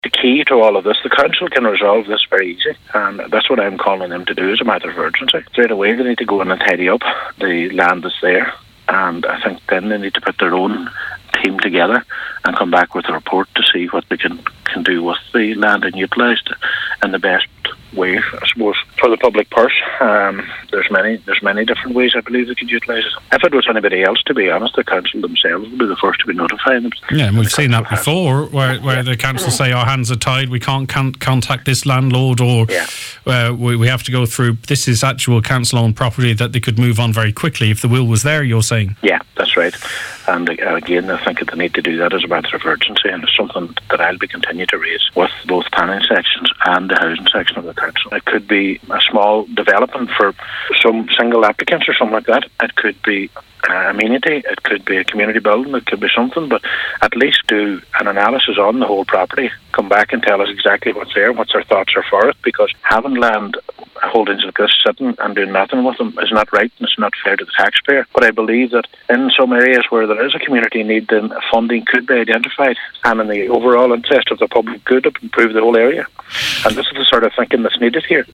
Speaking on the Nine til Noon Show today, he said Donegal County Council which owns the land, needs to come up with a plan to develop it…………